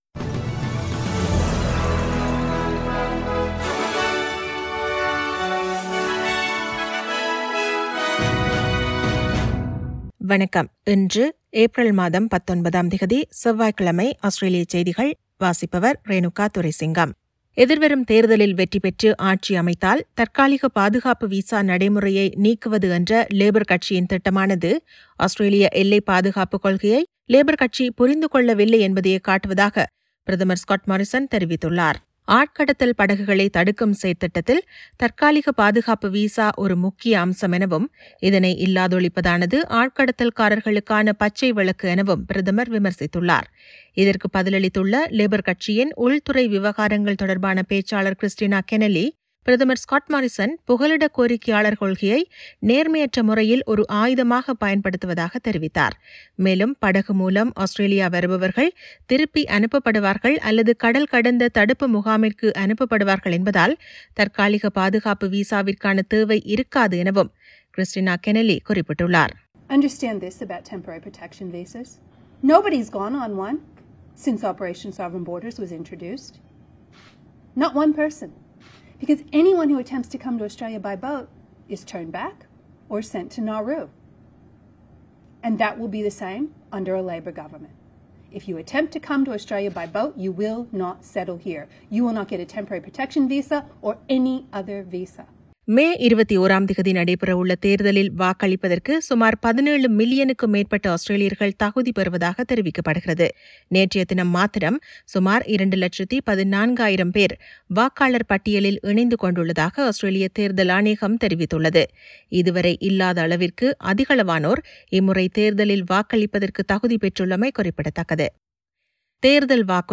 Australian news bulletin for Tuesday 19 April 2022.